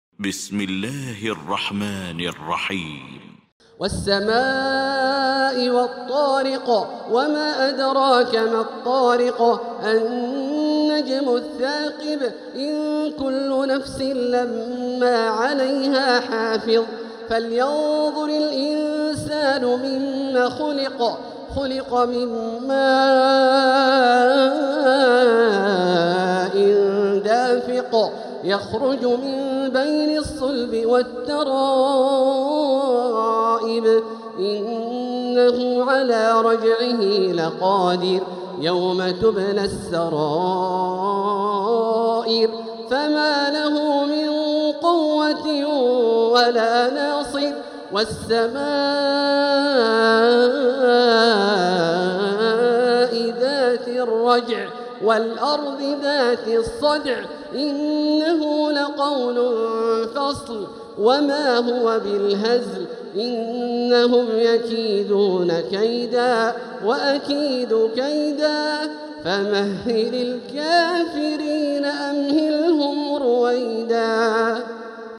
سورة الطارق Surat At-Tariq > مصحف تراويح الحرم المكي عام 1446هـ > المصحف - تلاوات الحرمين